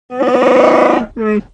Звуки Чубакки